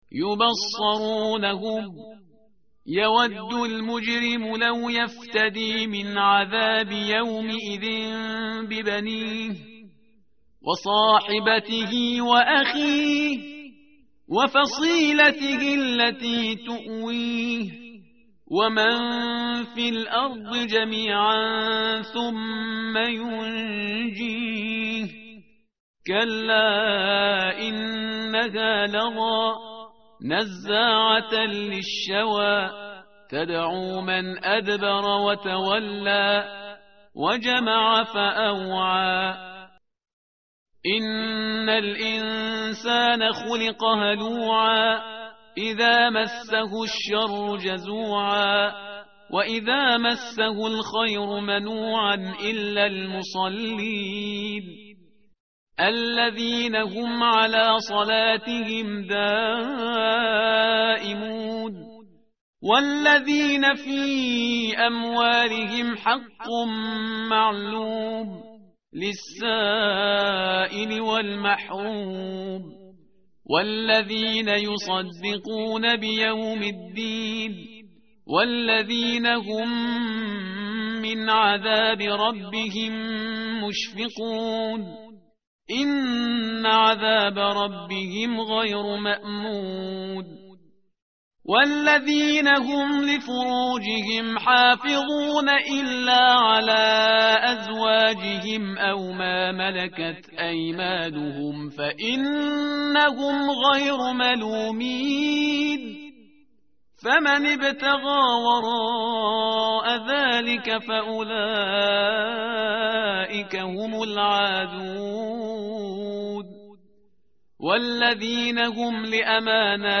متن قرآن همراه باتلاوت قرآن و ترجمه
tartil_parhizgar_page_569.mp3